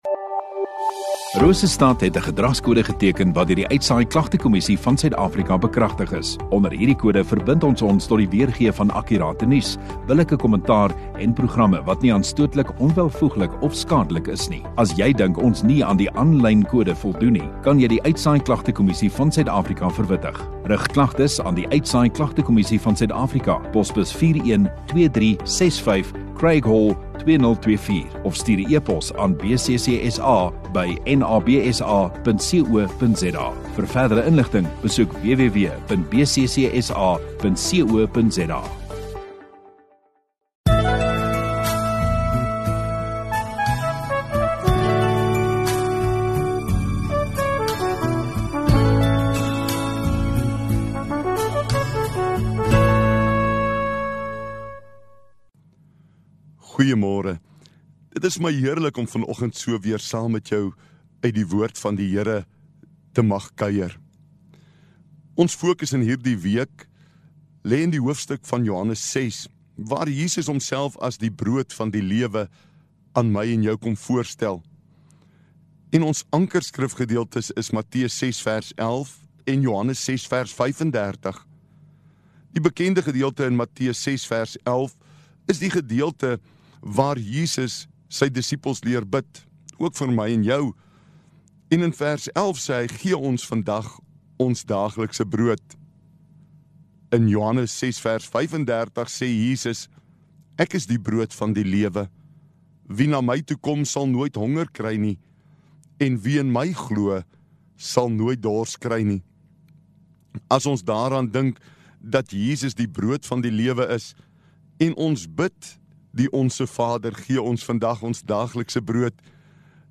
29 Aug Donderdag Oggenddiens